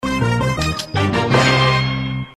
электронные